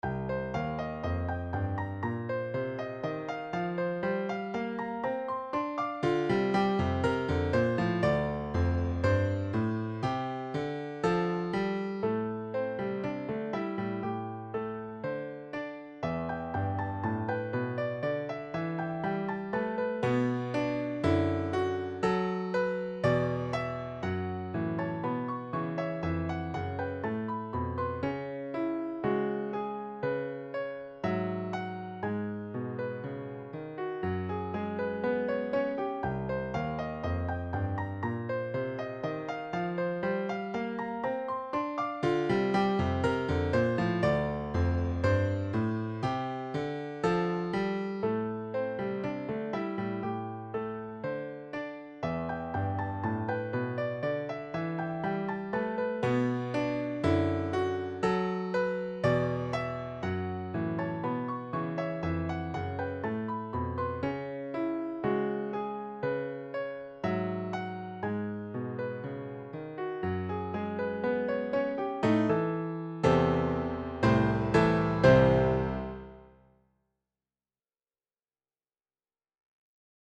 These are Garage Band mp3 files and are quite large.